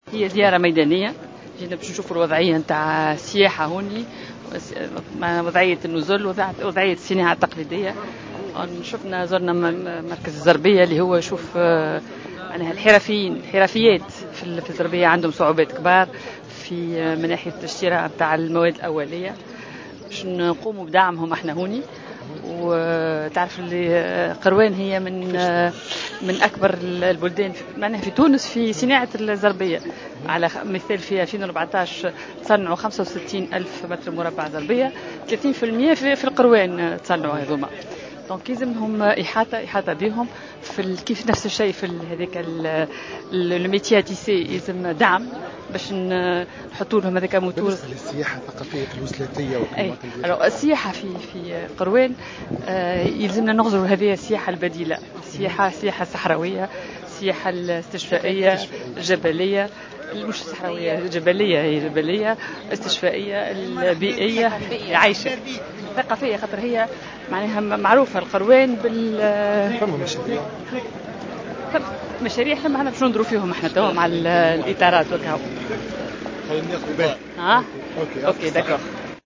وتحدثت الوزيرة لمراسل الجوهرة أف أم على ضرورة دعم السياحة البديلة بالجهة على غرار السياحة الثقافية والجبلية والاستشفائية، مضيفة أنها ستنظر في ملف المشاريع المتعلقة بتطوير السياحة وقطاع الصناعات التقليدية مع إطارات الولاية.